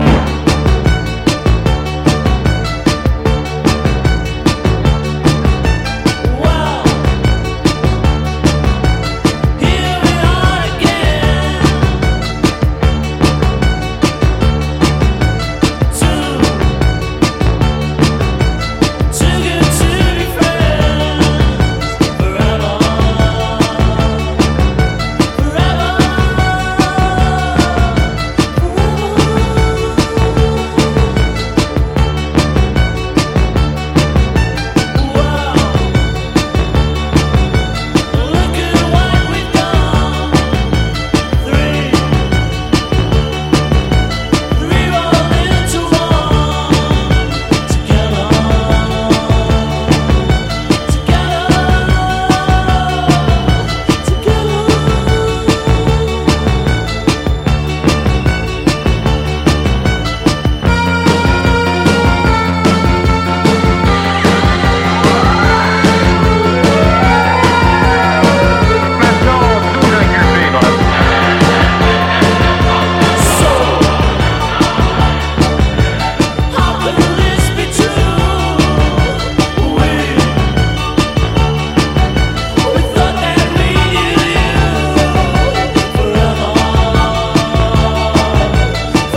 WORLD / REGGAE / ROOTS / CHRISTMAS
クリスマスじゃなくてもかけたくなる多幸感大爆発のレゲエ・クリスマス名作！